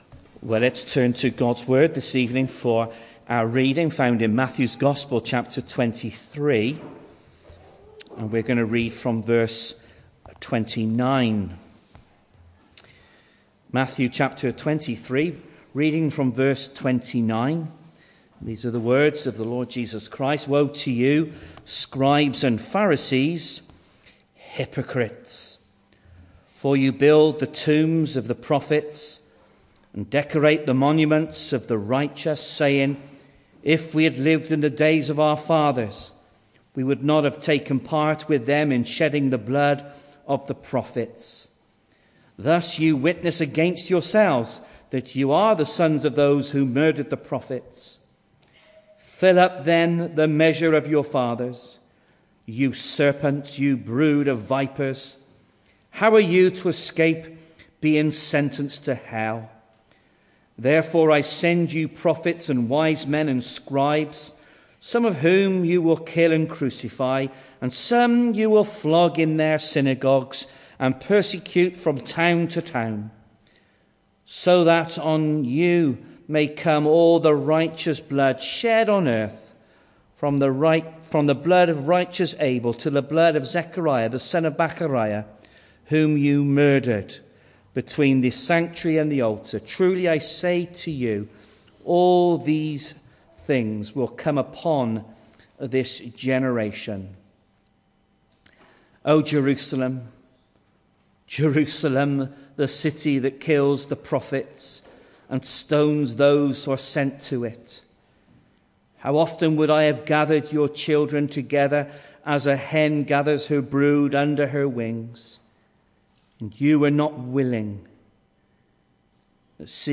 sermon recordings. The 9th of February saw us host our Sunday morning service from the church building, with a livestream available via Facebook.